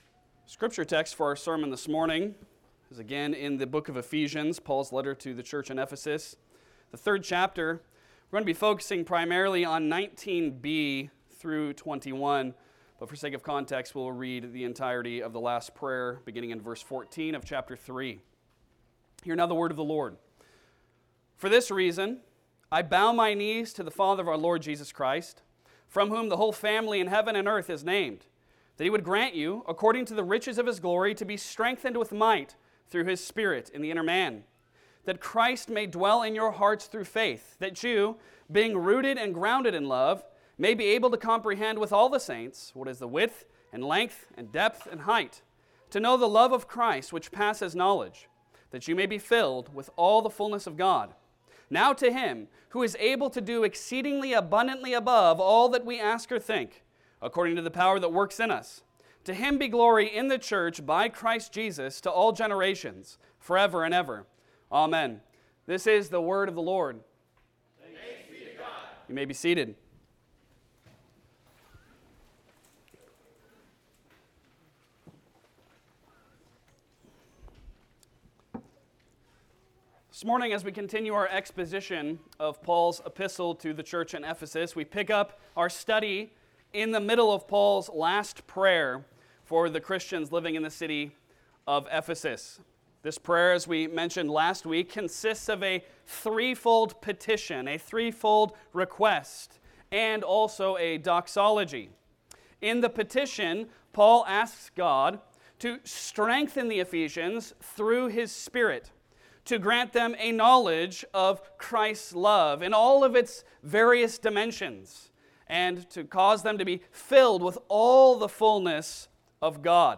Passage: Ephesians 3:19b-21 Service Type: Sunday Sermon